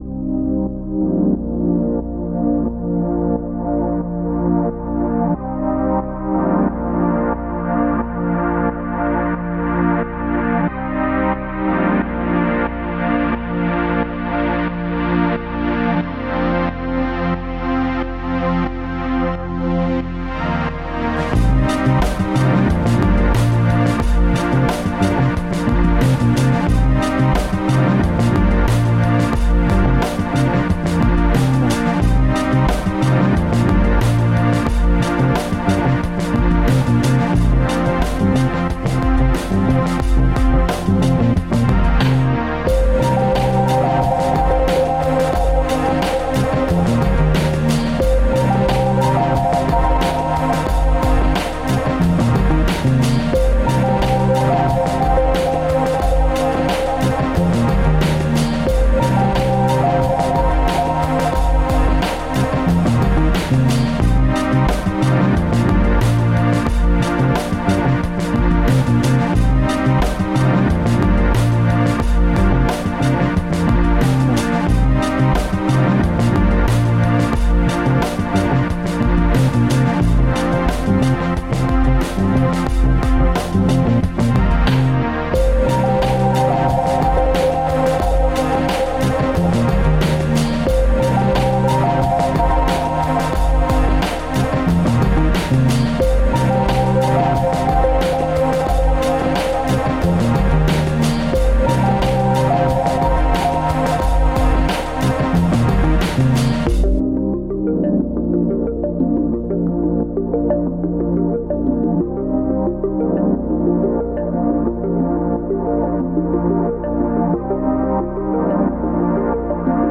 The most uplifting electronic music.
Uplifting, energizing, melodic, and emotionally-hitting.